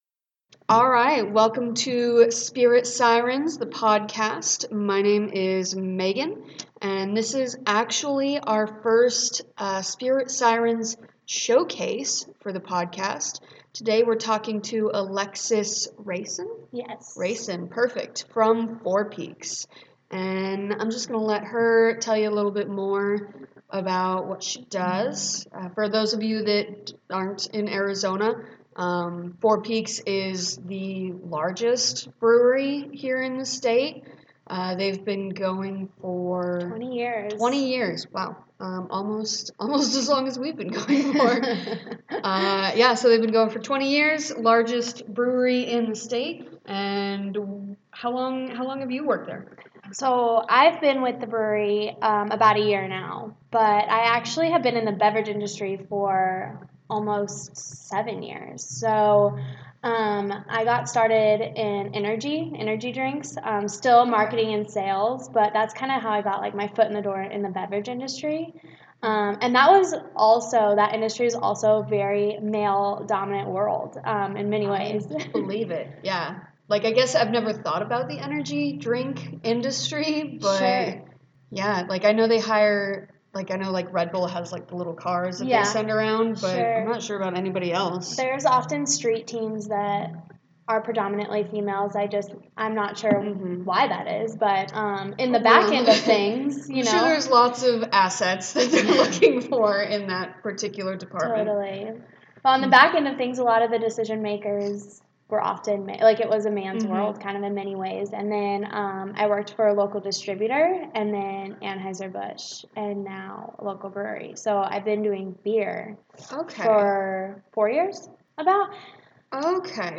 Interview
This is our third Spirit Sirens Showcase and our first in interview format!